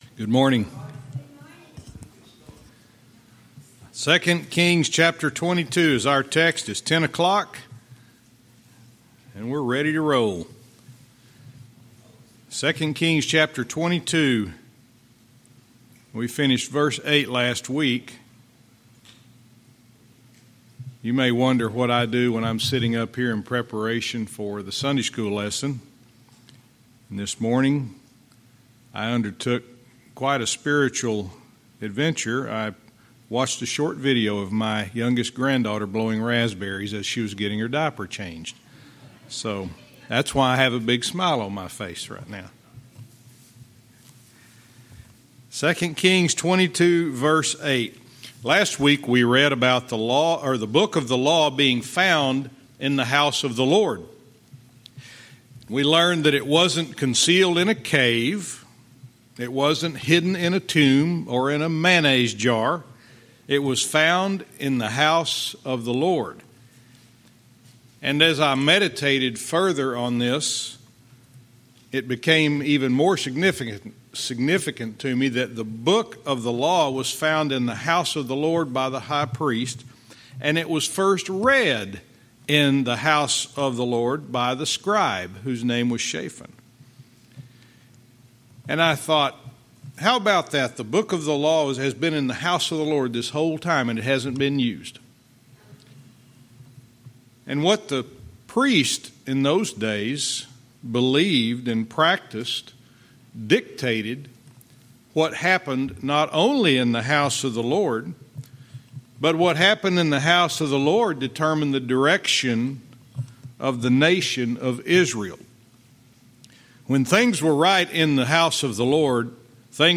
Verse by verse teaching - 2 Kings 22:9-11